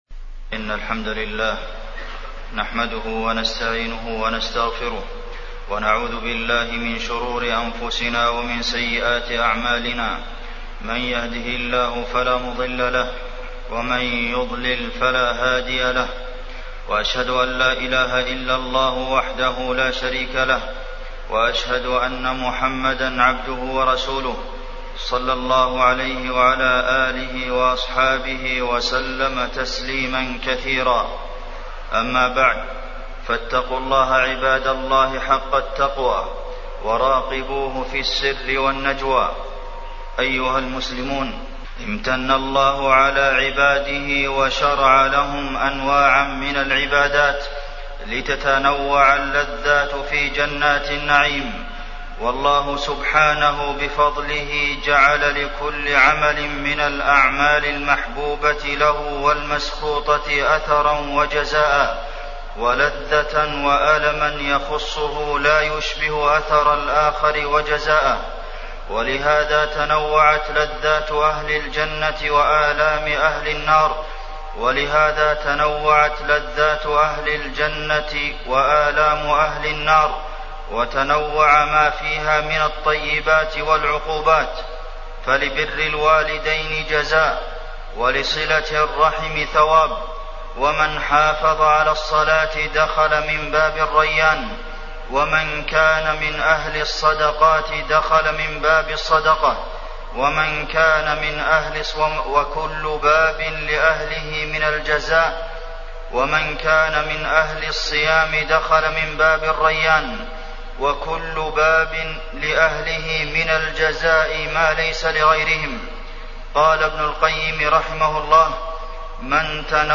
تاريخ النشر ١٢ رمضان ١٤٢٩ هـ المكان: المسجد النبوي الشيخ: فضيلة الشيخ د. عبدالمحسن بن محمد القاسم فضيلة الشيخ د. عبدالمحسن بن محمد القاسم أبواب الخير